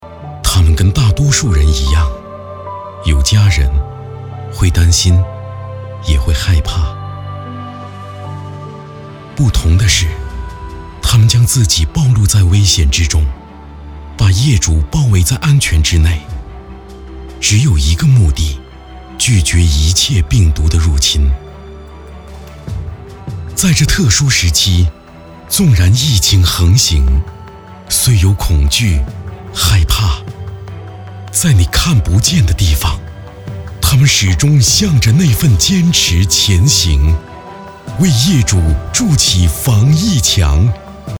商业旁白配音男356号
深情缓慢 微电影旁白配音